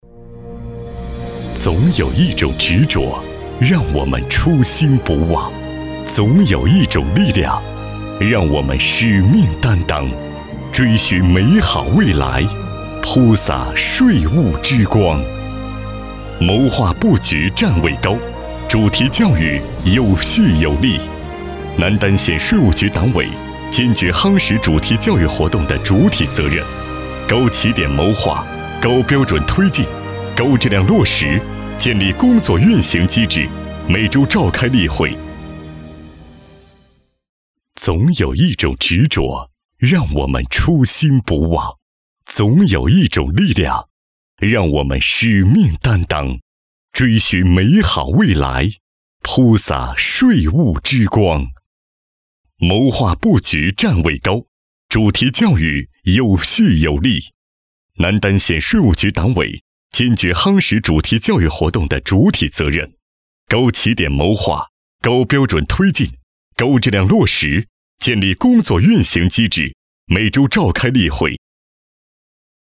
162男-大气恢宏
特点：大气浑厚 稳重磁性 激情力度 成熟厚重
风格:浑厚配音